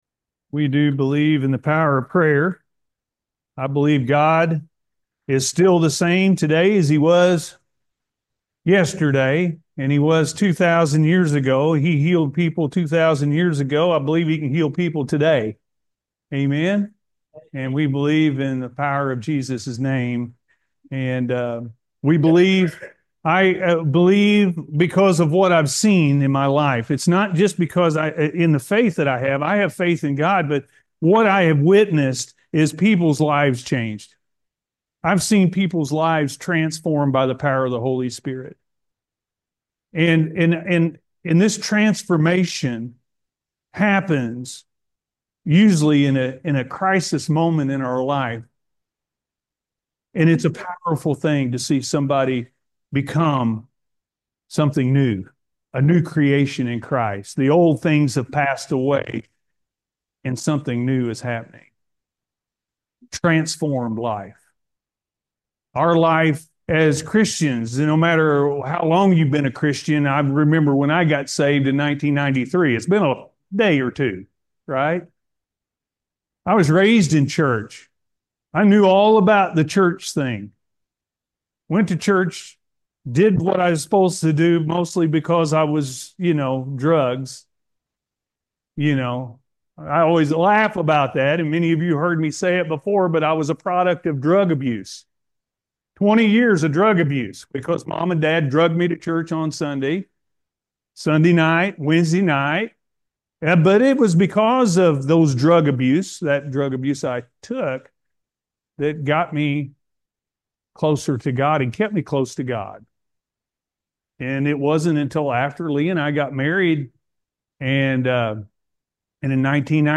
A Transformed Life-A.M. Service – Anna First Church of the Nazarene
A Transformed Life-A.M. Service